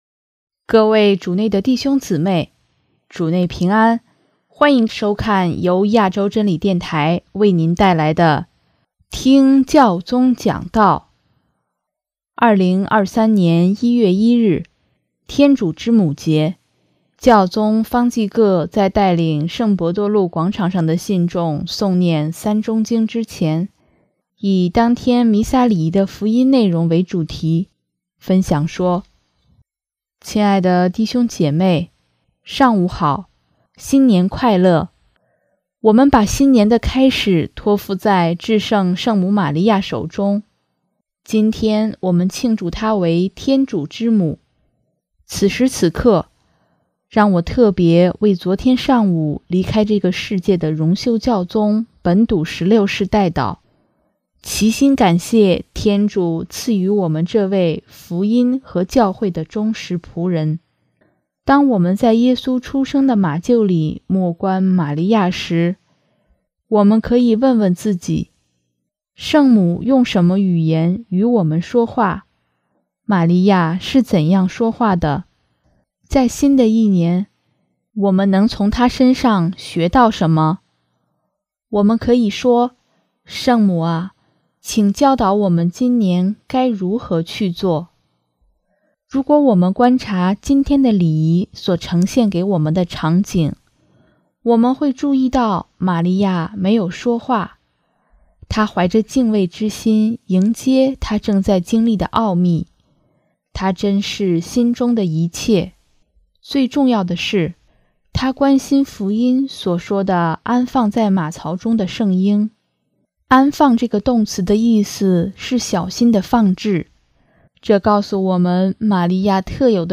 2023年1月1日，天主之母节，教宗方济各在带领圣伯多禄广场上的信众诵念《三钟经》之前，以当天弥撒礼仪的福音内容为主题，分享说：